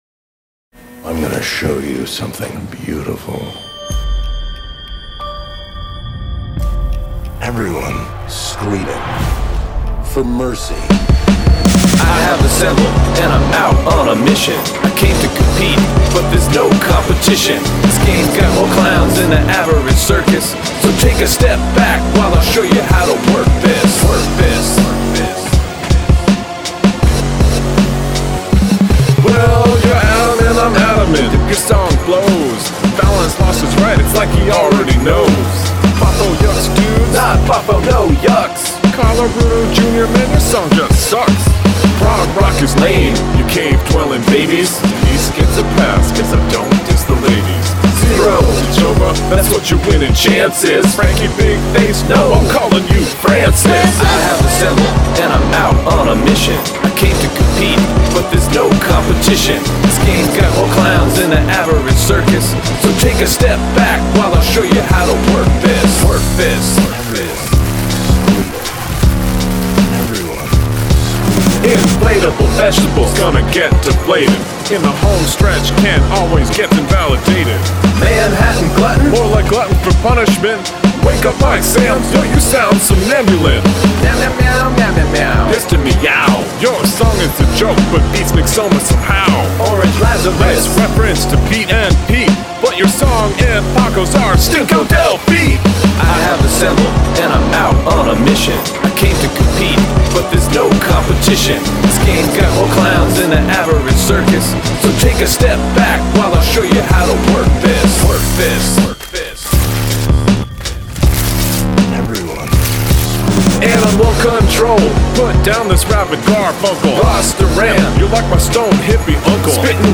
Use something from your garbage bin as an instrument
Not a bad rap for a non-rapper. Production works well.